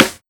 SNARE75.wav